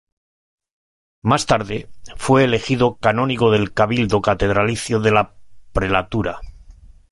pre‧la‧tu‧ra
/pɾelaˈtuɾa/